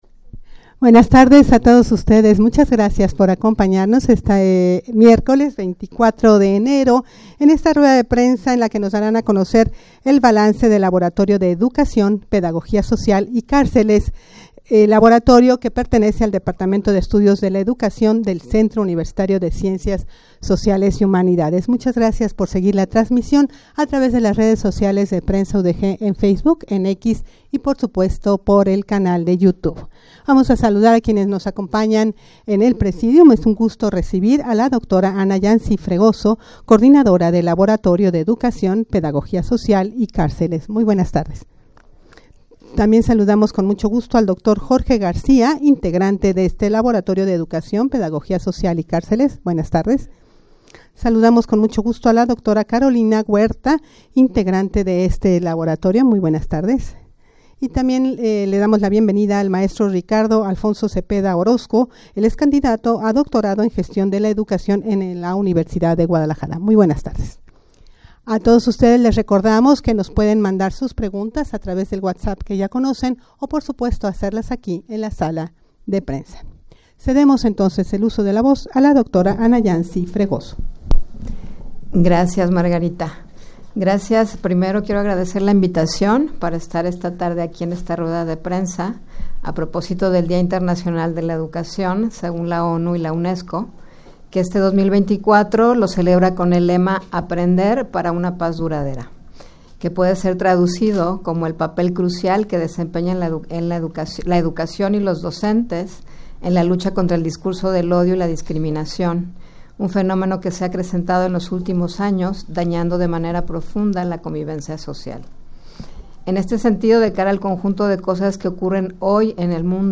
Audio de la Rueda de Prensa
rueda-de-prensa-balance-del-laboratorio-de-educacion-pedagogia-social-y-carceles.mp3